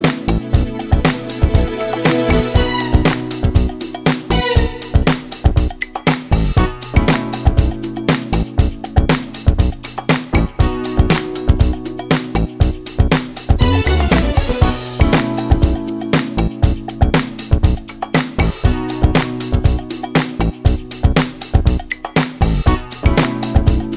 No Guitars or Backing Vocals Pop (1980s) 5:24 Buy £1.50